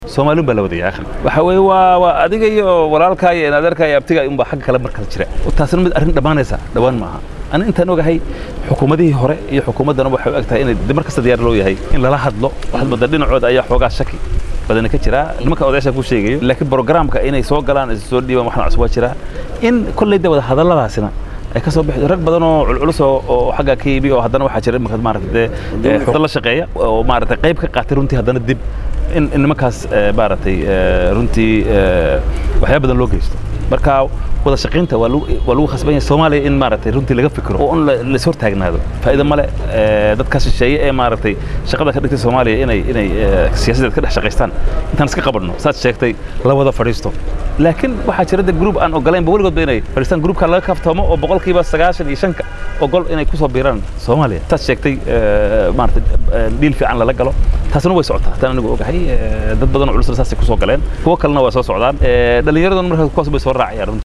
Xildhibaan Xuseen Carab Ciise Gudoomiyaha Guddiga Difaaca Baarlamaanka federaalka Soomaaliya ayaa ka hadlay caqabad Soomaaliya hortaagan in ay ka dhalato dowlad iyo waliba qaabka ugu wabaagsan ee lagu heli karo dowladnimada Soomaaliya.